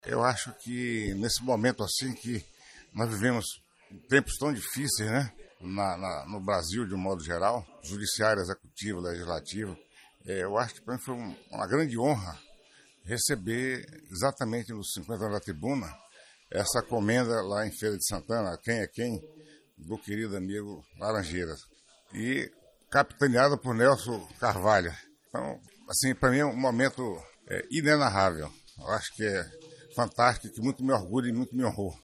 A solenidade ocorreu no Casarão Fróes da Mota, na cidade de Feira de Santana, no dia 12/12, e marcou o encerramento das comemorações pelos 50 anos do Jornal Tribuna da Bahia.